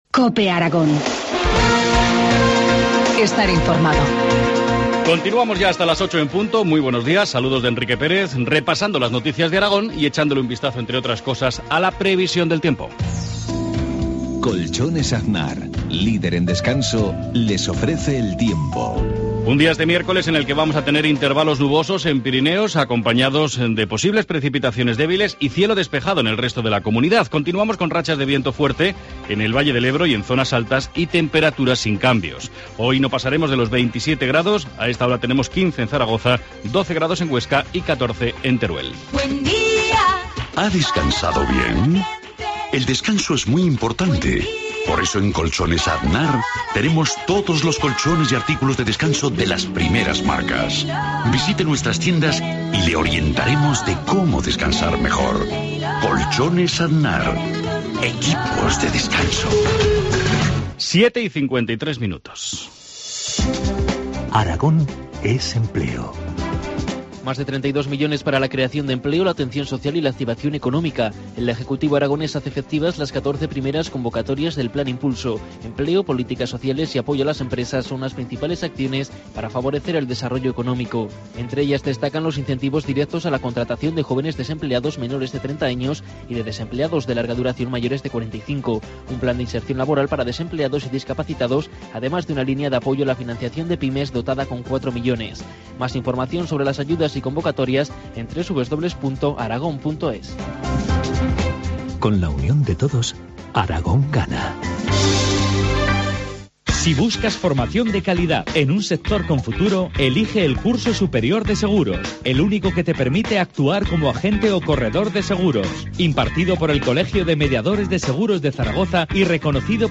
Informativo matinal, miércoles 18 de septiembre, 7.53 horas